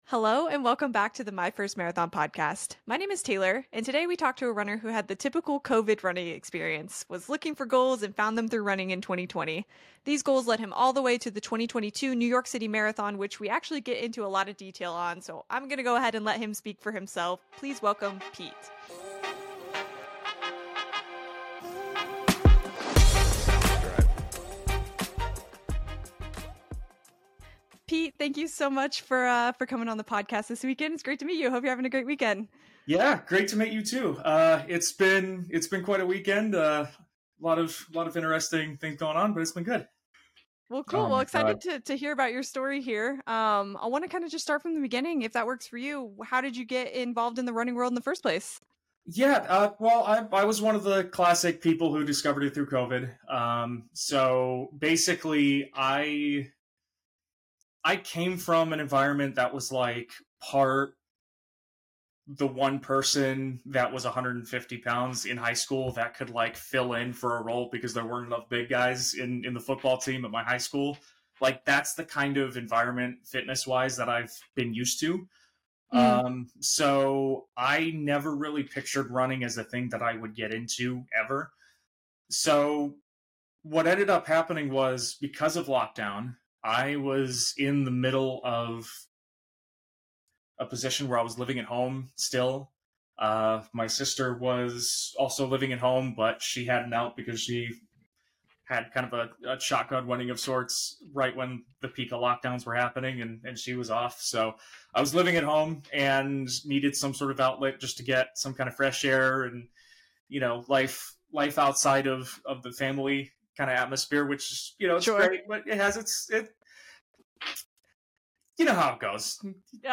On today's episode we interview a runner with the classic couch to 5k during COVID pipeline where he was searching for goals and a way to get outside, and happened upon running!